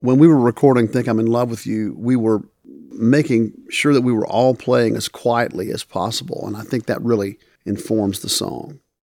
Chris Stapleton talks about recording “Think I’m In Love With You.”